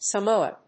音節Sa・mo・a 発音記号・読み方
/səmóʊə(米国英語), səmˈəʊə(英国英語)/